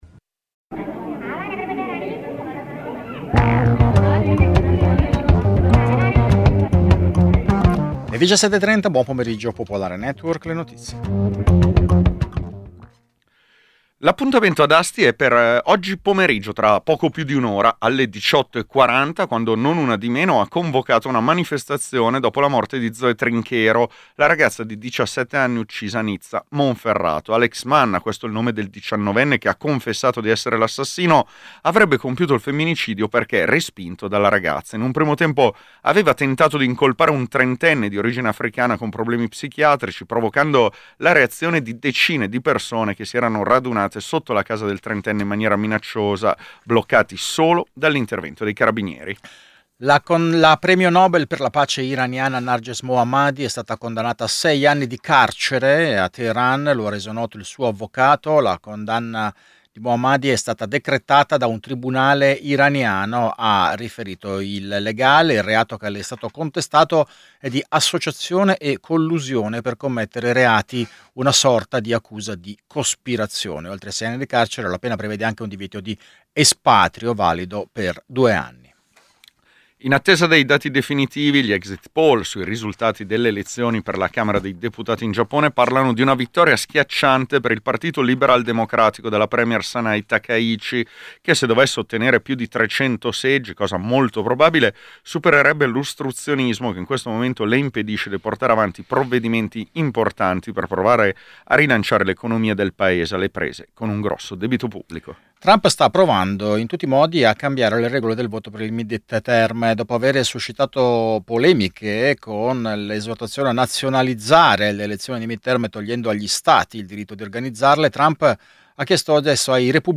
Edizione breve del notiziario di Radio Popolare. Le notizie. I protagonisti. Le opinioni. Le analisi.